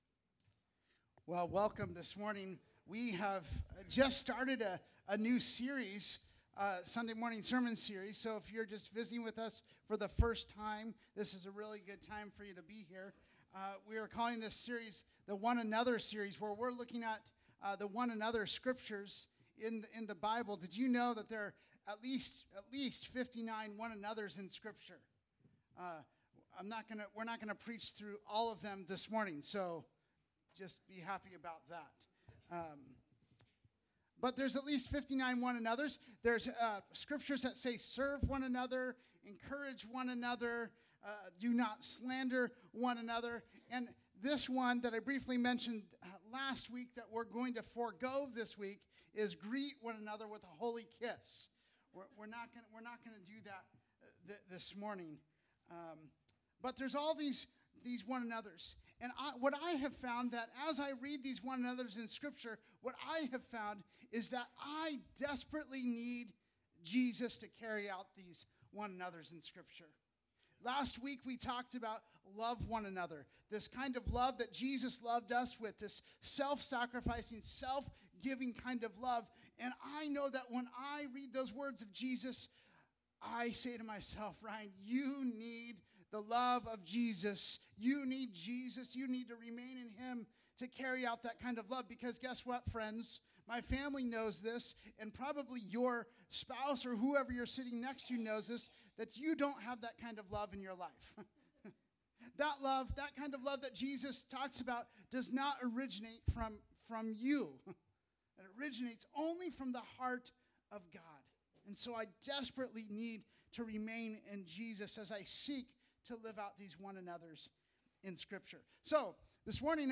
Audio Sermons | Tonasket Free Methodist Church